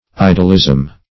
Idolism \I"dol*ism\, n. The worship of idols.